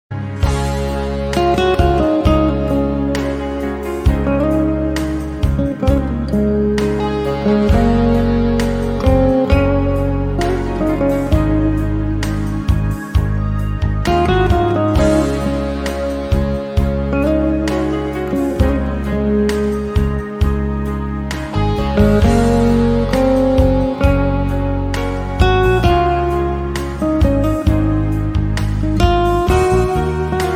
• Качество: 160, Stereo
гитара
спокойные
без слов
инструментальные
расслабляющие
Красивая игра на гитаре